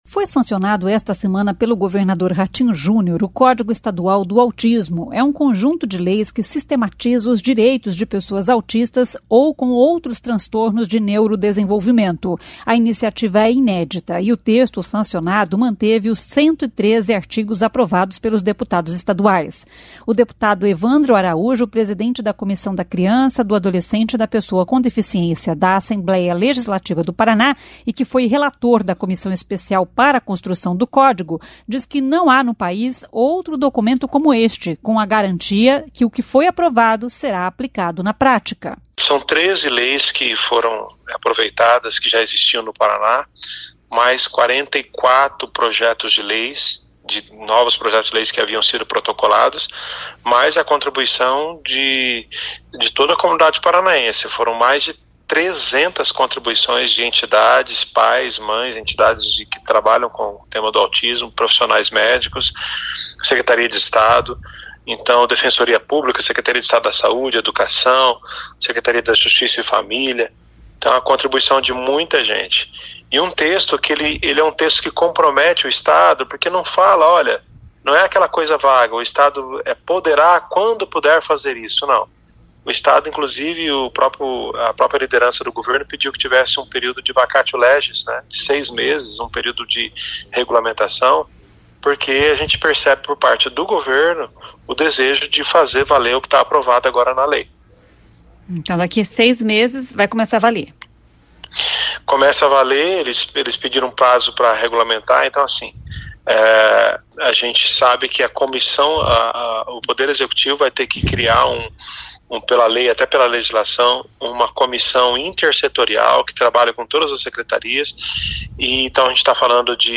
O deputado Evandro Araújo, presidente da Comissão da Criança, do Adolescente e da Pessoa com Deficiência da Assembleia Legislativa do Paraná e que foi relator da Comissão Especial para a construção do código, diz que não há no país outro documento como este, com a garantia que o que foi aprovado será aplicado na prática.